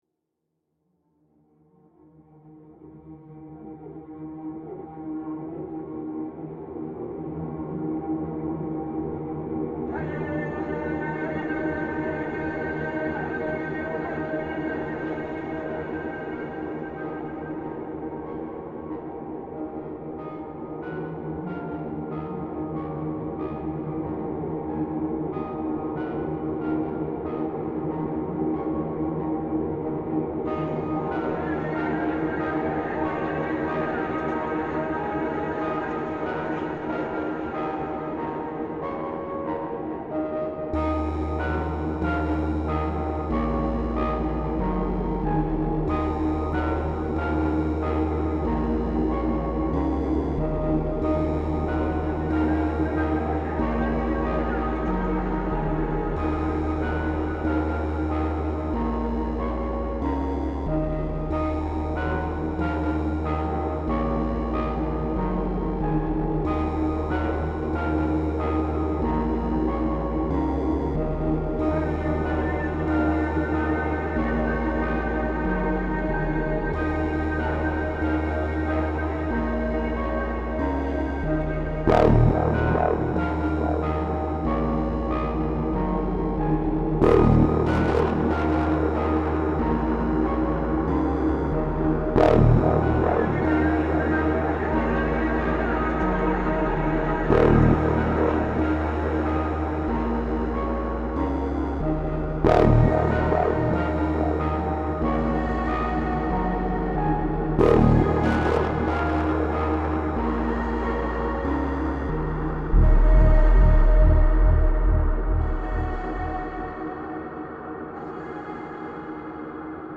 Essaouira call to prayer reimagined